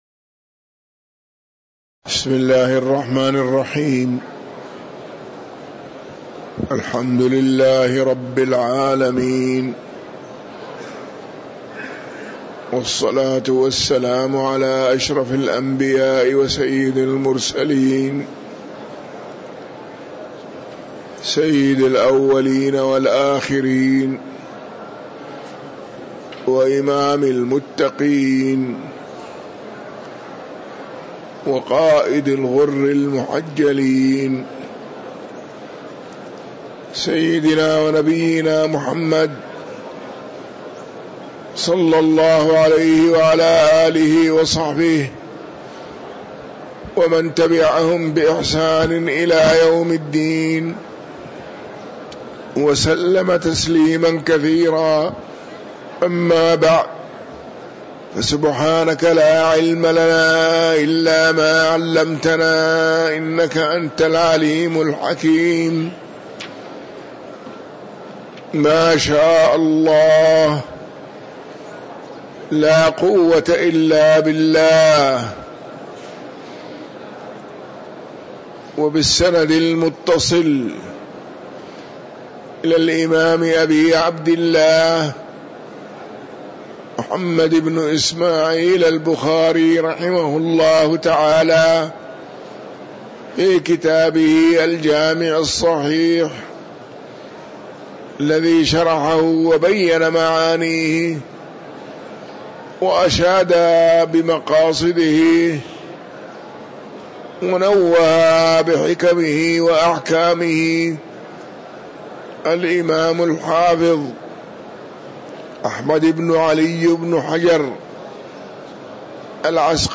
تاريخ النشر ٢٥ جمادى الآخرة ١٤٤٥ هـ المكان: المسجد النبوي الشيخ